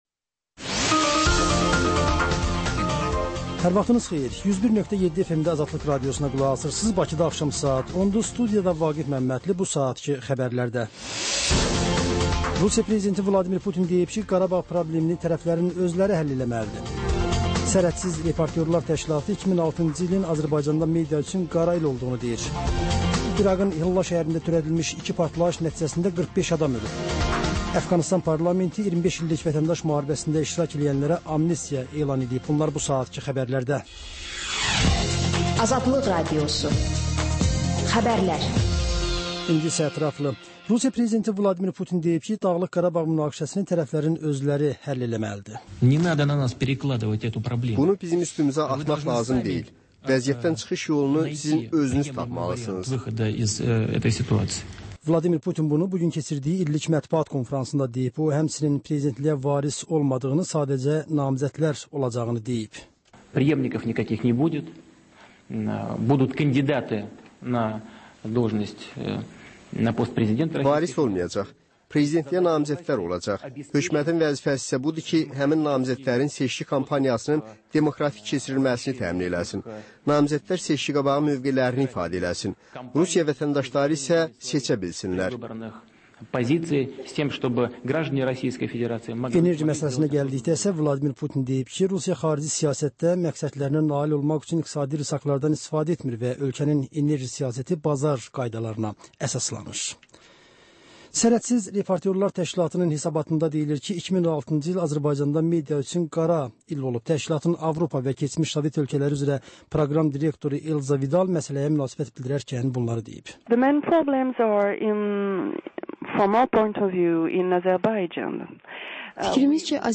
Xəbər, reportaj, müsahibə. Sonra: Və ən son: Qlobus: xaricdə yaşayan azərbaycanlılar.